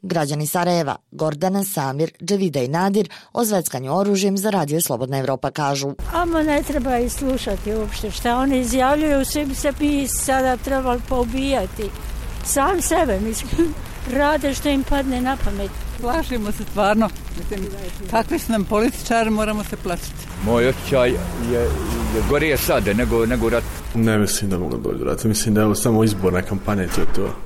Građani Sarajeva o zveckanju oružjem